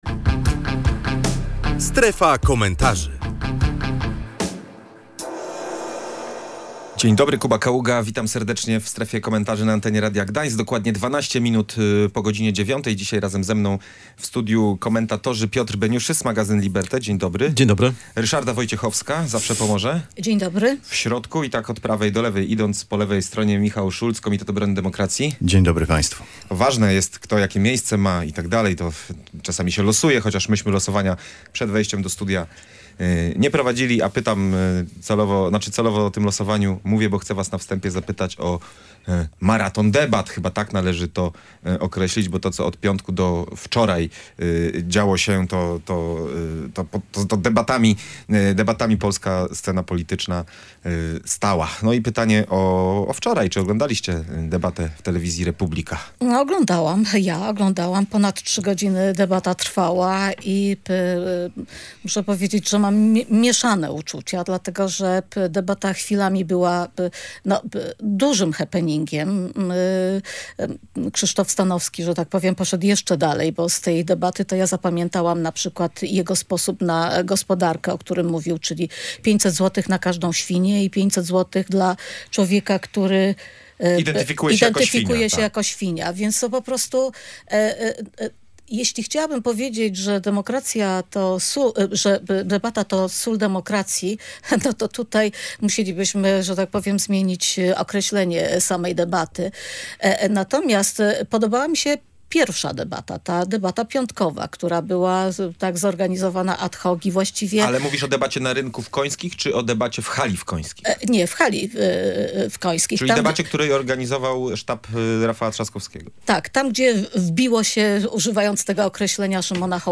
Na temat poniedziałkowej debaty rozmawialiśmy w „Strefie Komentarzy”.